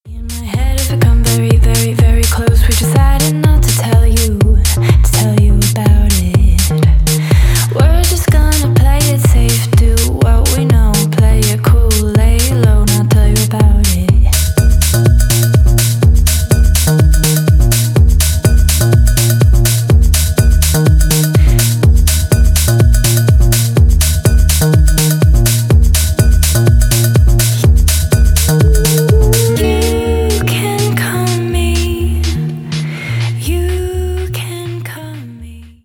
Поп Музыка